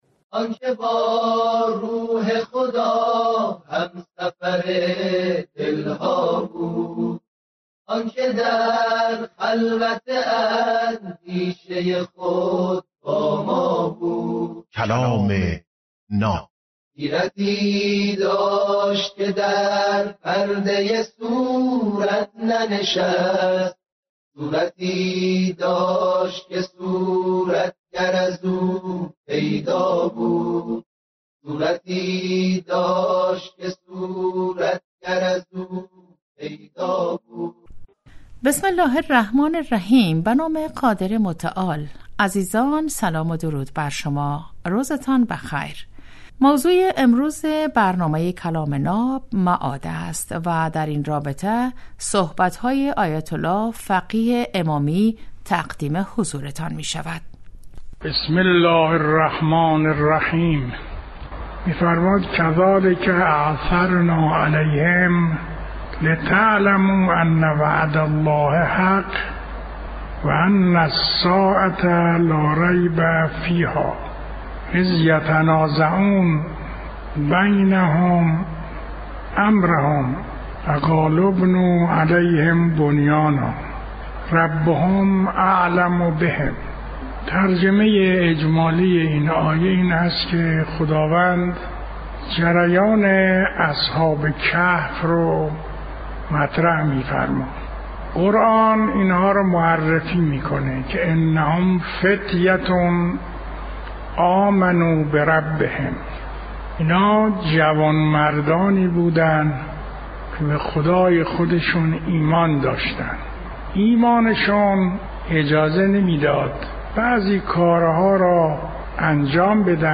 کلام ناب برنامه ای از سخنان بزرگان است که هر روز ساعت 7:35 عصر به وقت افغانستان به مدت 10دقیقه پخش می شود.